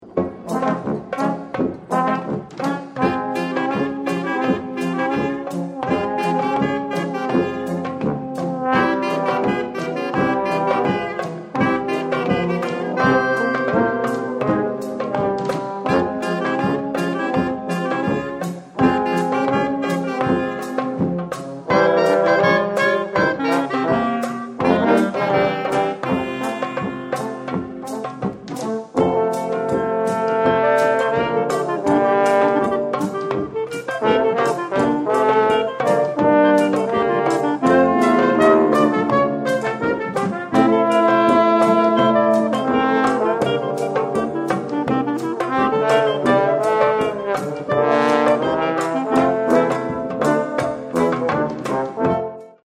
Accueil Pasos dobles & exotiques Salomé Salomé Arthur Rebner Écoutez Salomé de Arthur Rebner interpreté par la Fanfare Octave Callot Téléchargez le morceau ← Nina Pancha ↑ Pasos dobles & exotiques Créole Belles →